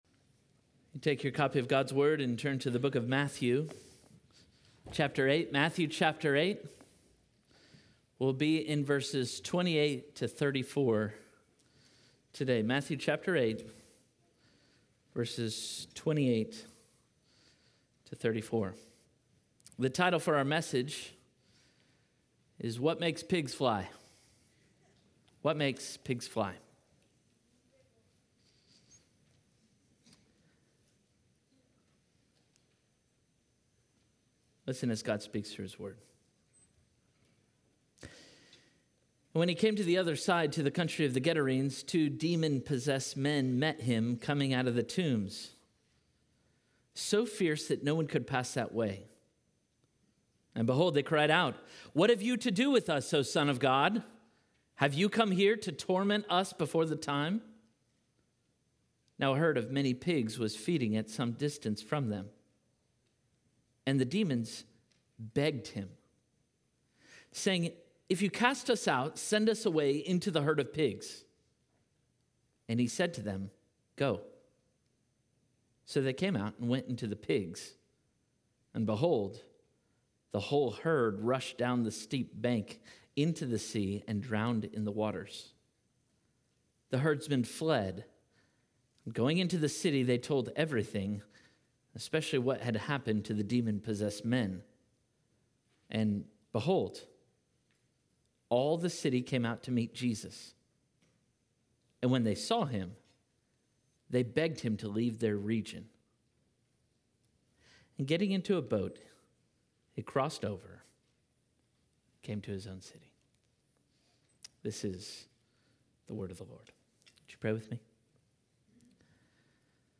Parkway Sermons What Makes Pigs Fly?